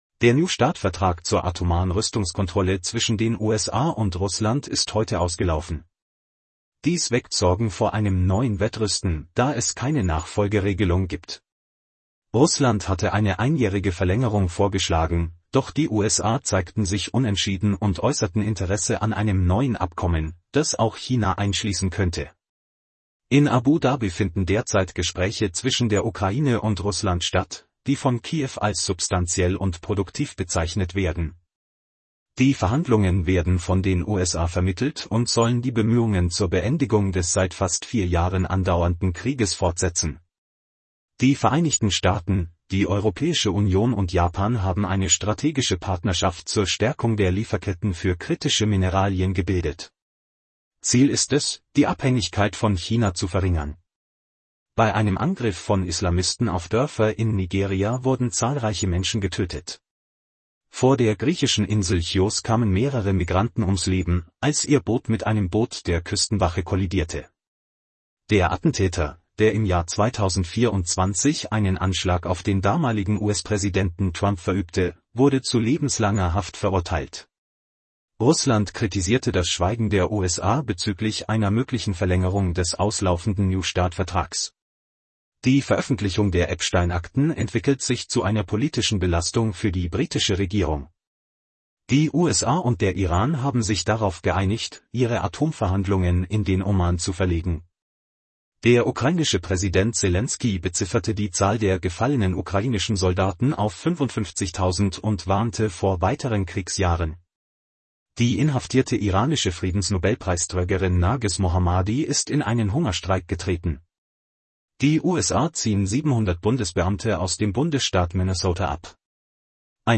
Dies ist ein Nachrichten-Podcast aus Dutzenden von Kurzberichten, zum hands-free Hören beim Autofahren oder in anderen Situationen.